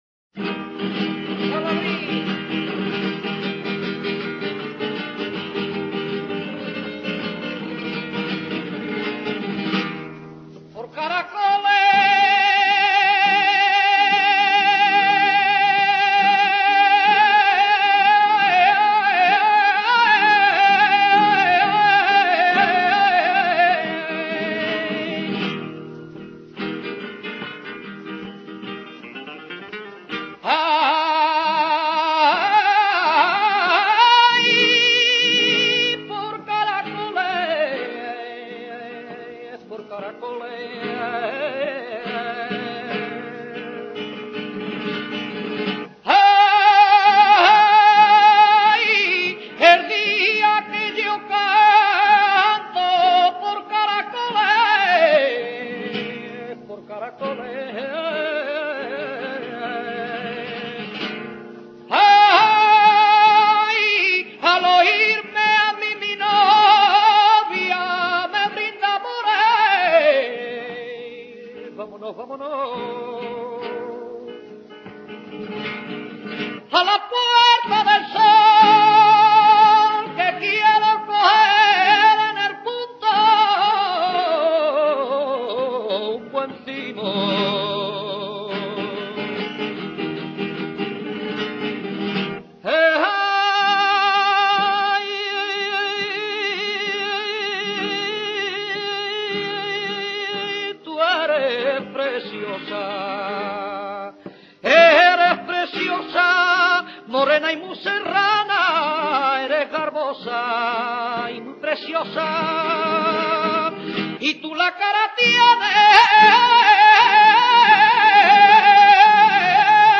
caracoles.mp3